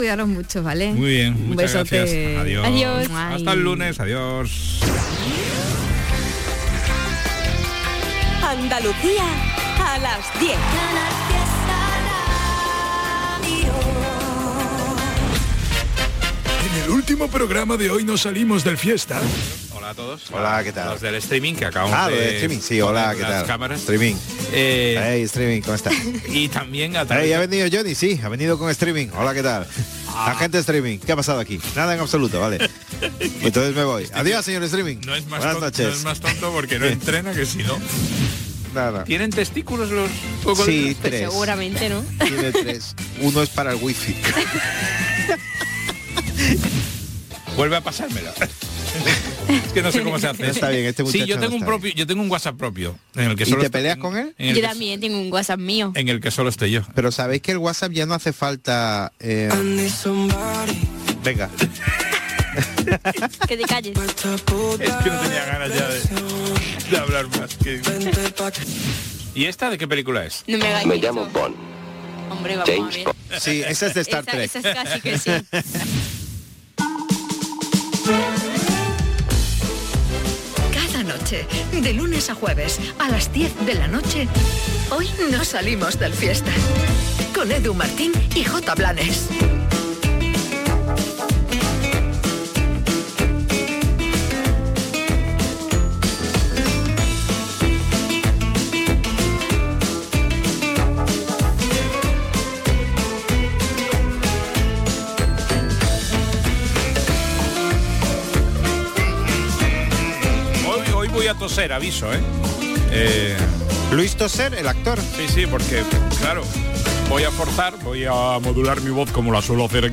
Buena música, humor y alguna que otra reflexión para organizarte tus cosas. Canal Fiesta te ofrece un programa nocturno de noticias y curiosidades muy loco. Un late radio show para que te quedes escuchando la radio hasta que te vayas a dormir.